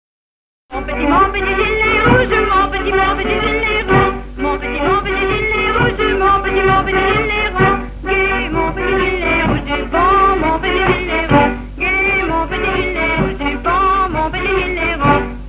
Couplets à danser
danse : branle : courante, maraîchine
Pièce musicale inédite